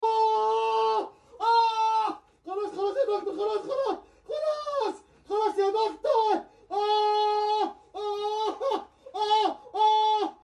Ahh Screaming